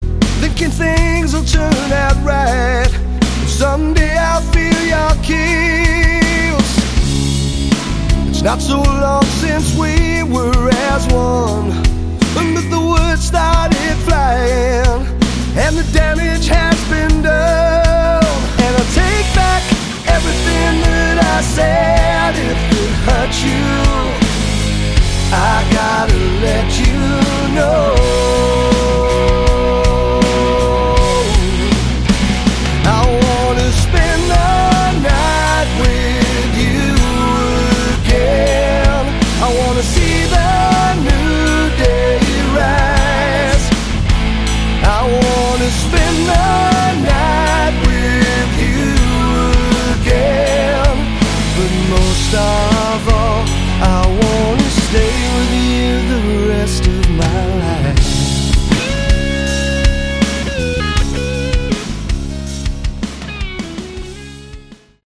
Category: Hard Rock
lead and backing vocals, bass, guitars
electric, acoustic and slide guitars
drums
kayboards, backing vocals